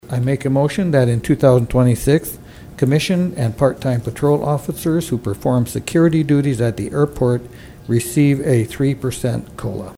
Harding also presented a pay increase for the City Commission…